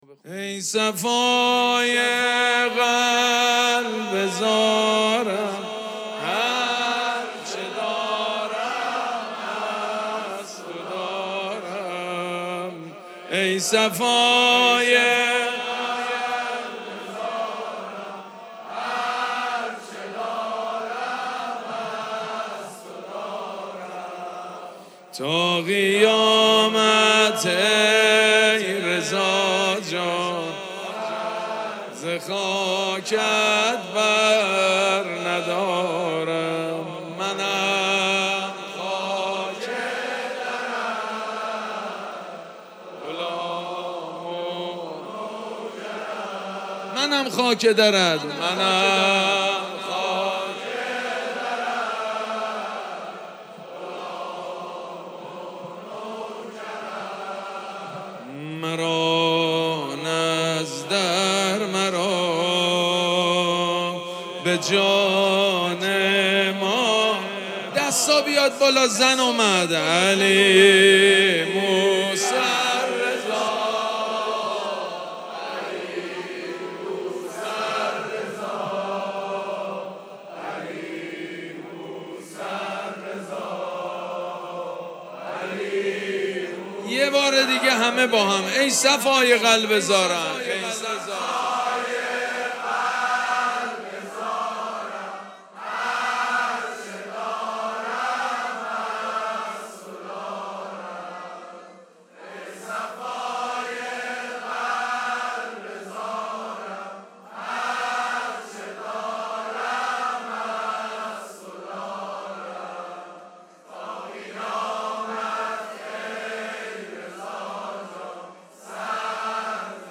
مراسم جشن ولادت امام رضا علیه‌السّلام
حسینیه ریحانه الحسین سلام الله علیها
شعر خوانی
مداح
حاج سید مجید بنی فاطمه